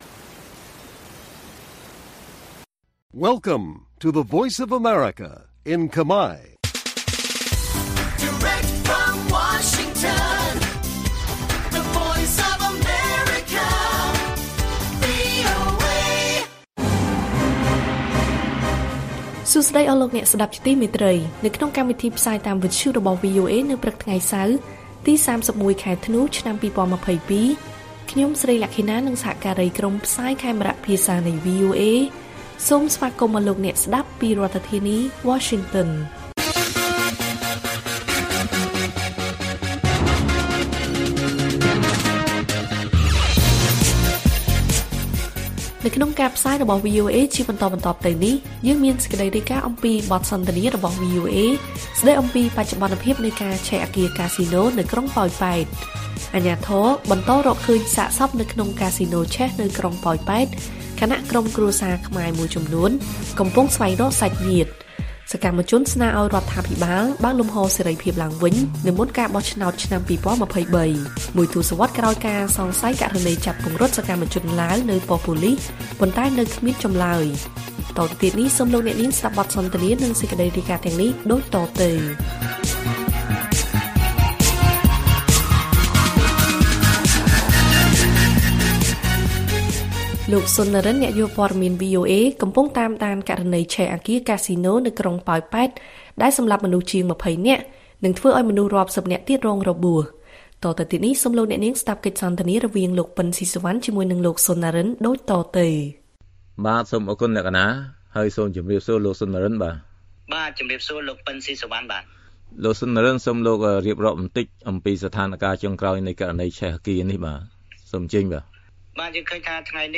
ព័ត៌មានពេលព្រឹក ៣១ ធ្នូ៖ បទសន្ទនារបស់ VOA ស្តីពីបច្ចុប្បន្នភាពនៃការឆេះអគារកាស៊ីណូនៅក្រុងប៉ោយប៉ែត